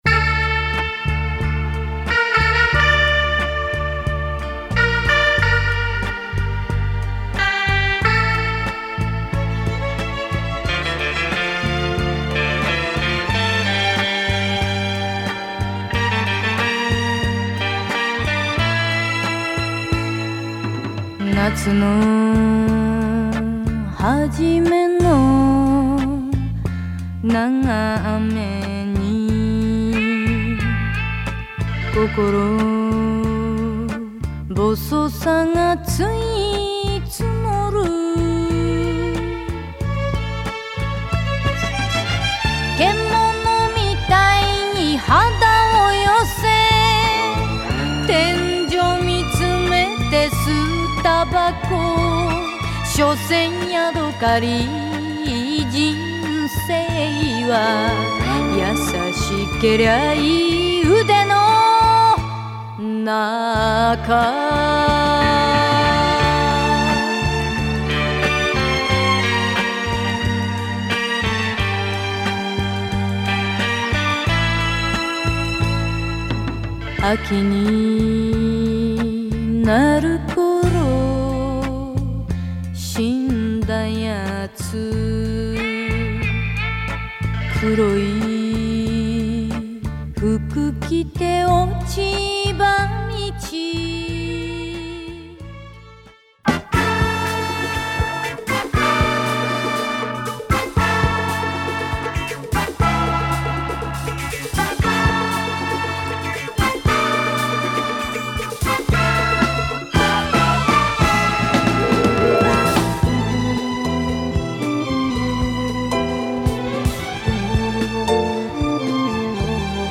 Sounds like a spaghetti western that takes place in japan